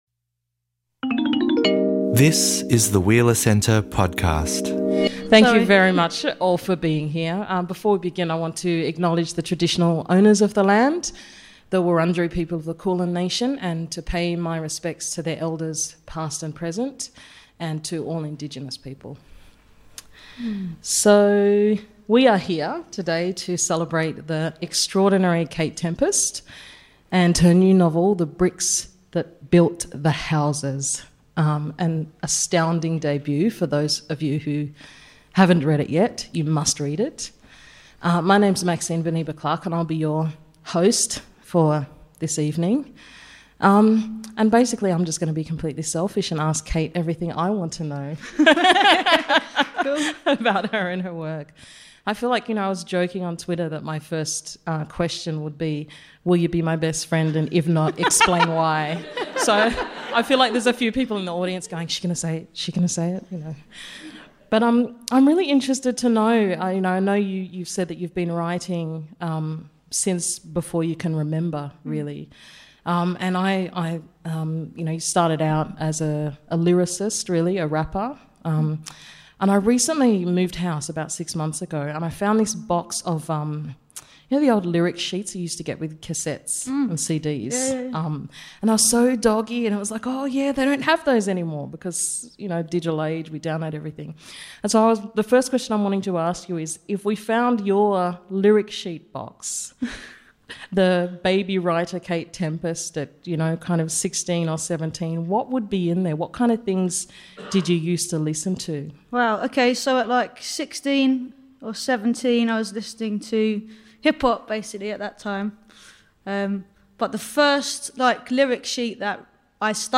Listen to this lively discussion between Kate Tempest – one of the most exciting new voices emerging from Britain today – and host Maxine Beneba Clarke. Musician, rapper, playwright, performance poet, novelist; it’s hard to know which title comes first when referring to Tempest.